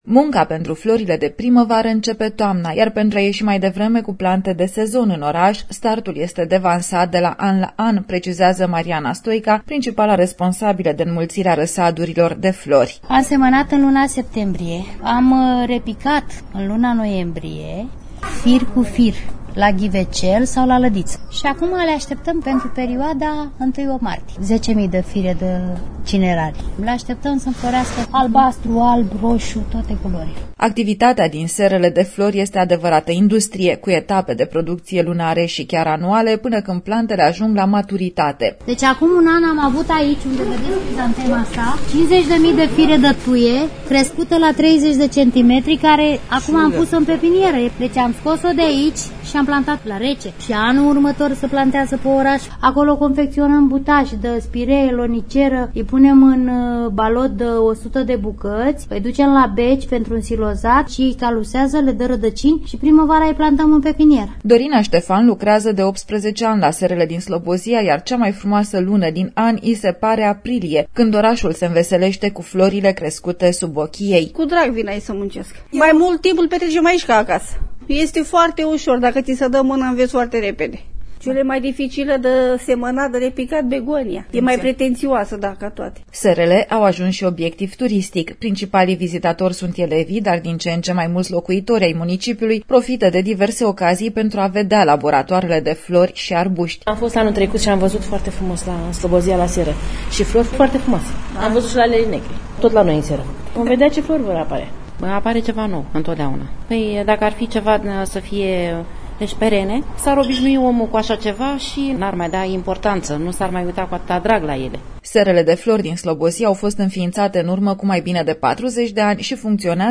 reportaj-sere-flori.mp3